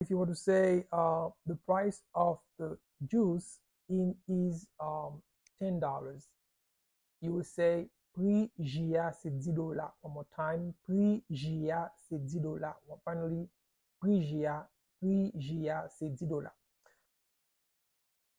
Pronunciation and Transcript:
How-to-say-The-price-of-the-juice-is-ten-dollars-in-Haitian-Creole-–-Pri-ji-a-se-di-dola-pronunciation.mp3